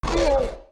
Звуки раптора
Звук охоты Раптора во второй версии